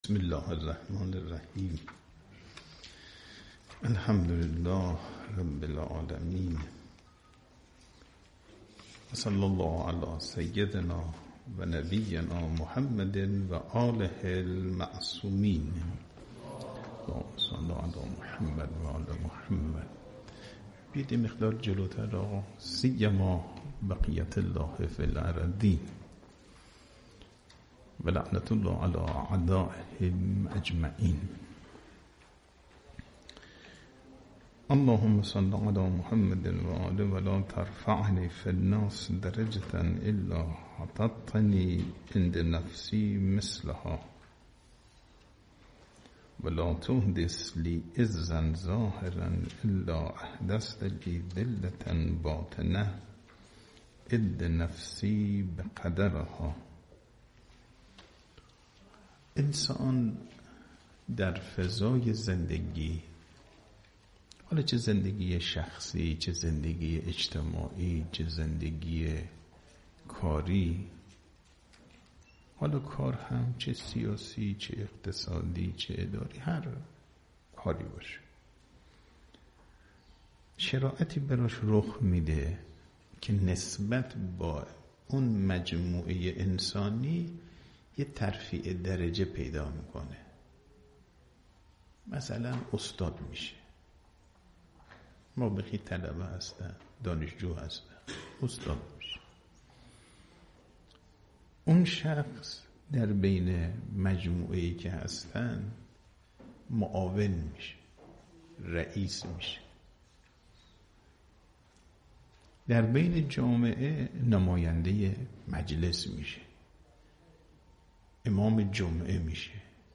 صوت | درس اخلاق آیت الله صفایی بوشهری در مدرسه علمیه امام خمینی (ره) بوشهر
حوزه/ جلسات هفتگی درس اخلاق حجت الاسلام والمسلمین صفایی بوشهری نماینده ولی فقیه در استان بوشهر در مدرسه علمیه امام خمینی (ره) بوشهر طبق روال هر هفته برگزار شد.